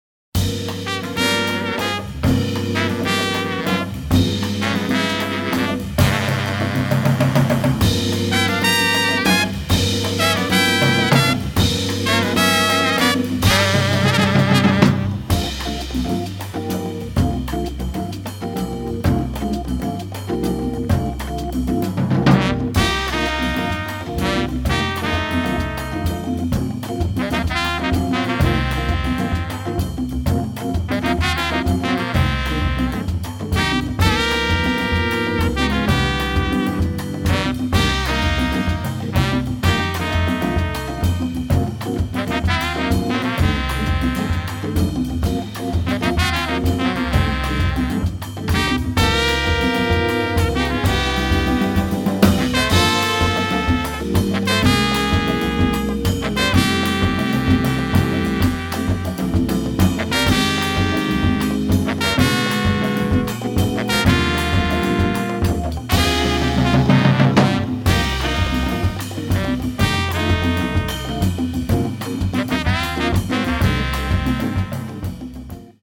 tenor and baritone saxes, vocals
trumpet
drums
percussion
Melvin Rhyne – hammond B3 organ
It’s got a totally different groove than the original.